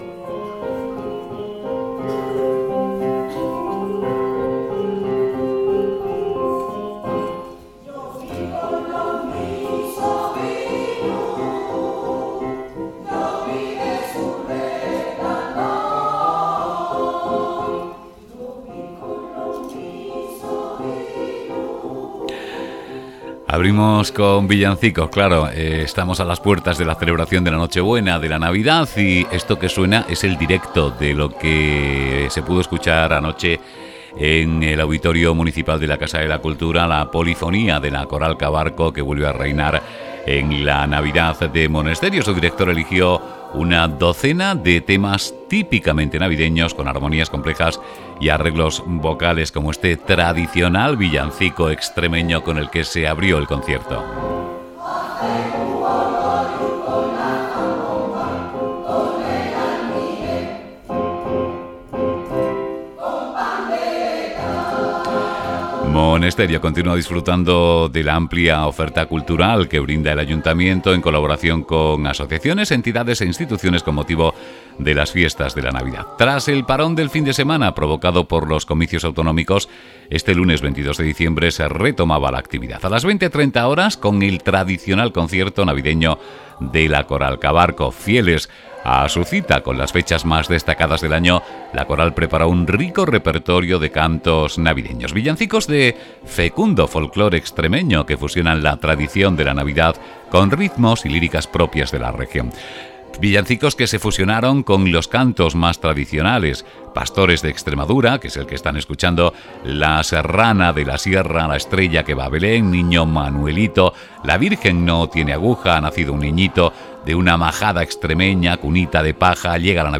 La polifonía de la coral Cabarco reina en la Navidad de Monesterio
ueOilCABARCONAVIDADCONCIERTO.mp3